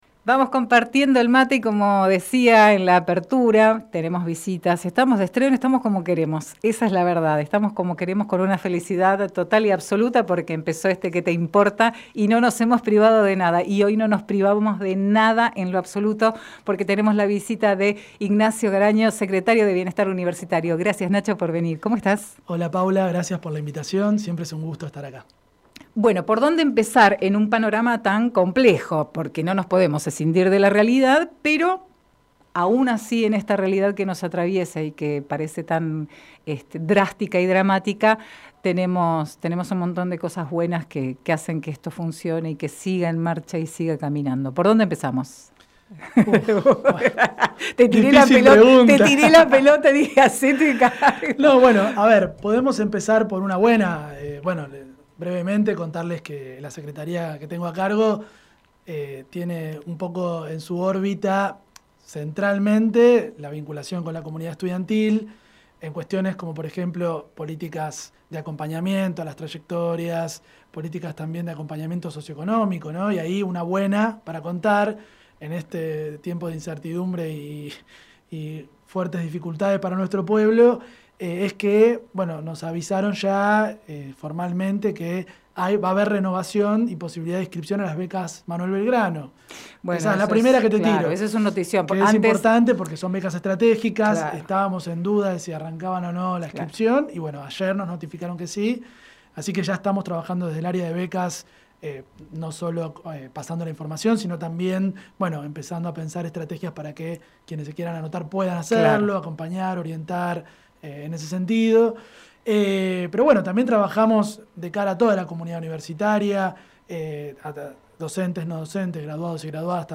ENTERVISTA REALIZADA EN QUÉ TE IMPORTA CON..